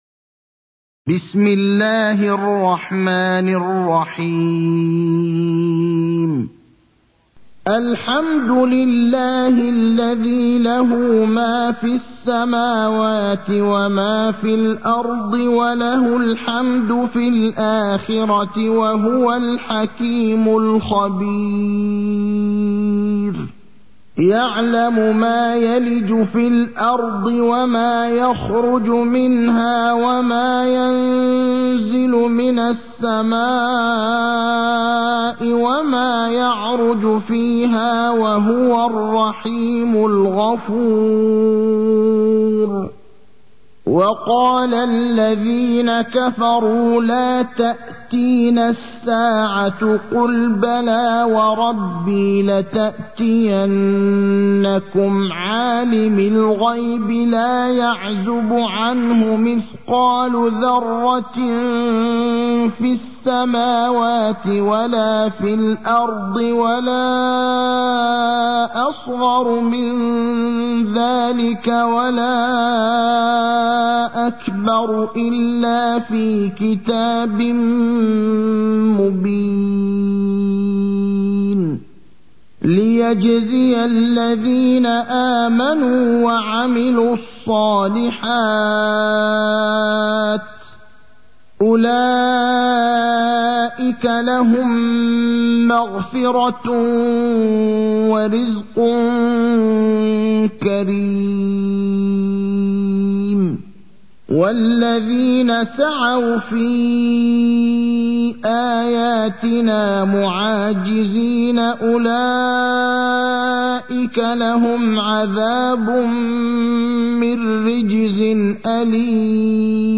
المكان: المسجد النبوي الشيخ: فضيلة الشيخ إبراهيم الأخضر فضيلة الشيخ إبراهيم الأخضر سبأ